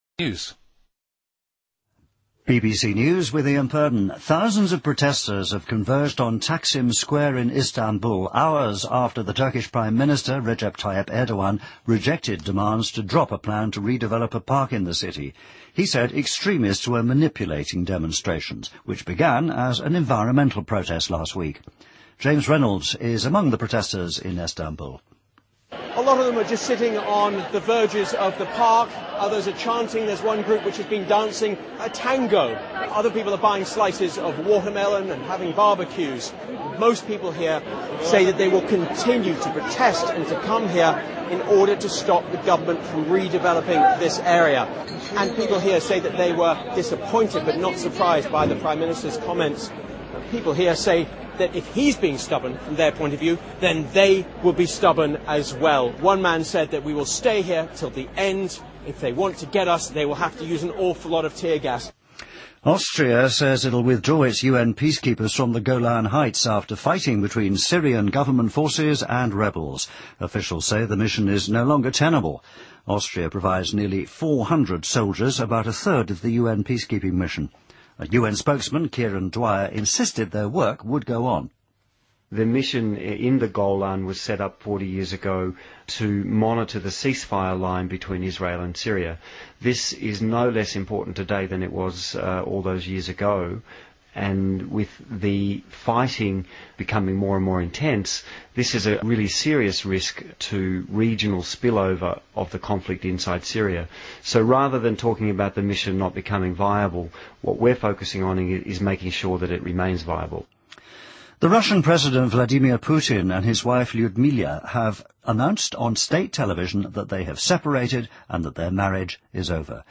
BBC news,2013-06-07